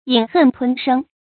飲恨吞聲 注音： ㄧㄣˇ ㄏㄣˋ ㄊㄨㄣ ㄕㄥ 讀音讀法： 意思解釋： 飲恨：強忍怨恨；吞聲：哭泣而不敢出聲。